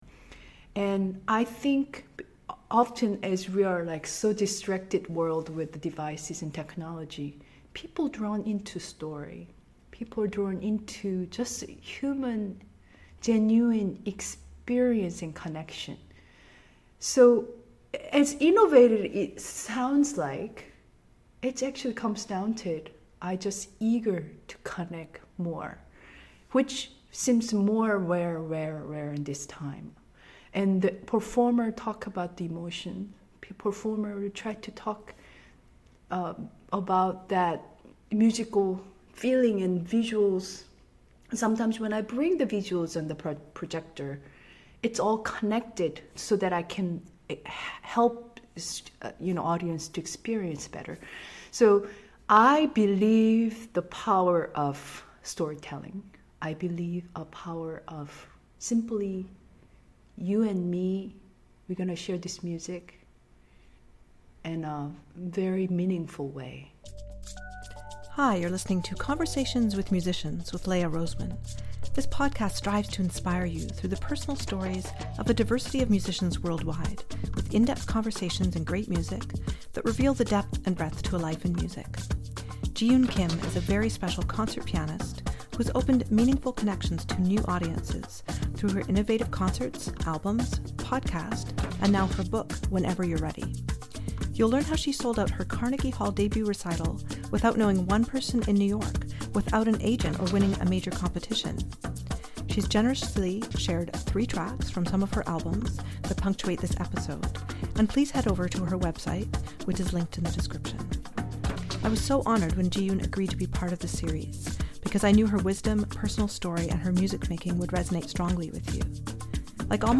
You’ll learn how she sold out her Carnegie Hall debut recital without knowing one person in New York, without an agent or winning a competition. She’s generously shared 3 tracks from some of her albums that punctuate this episode.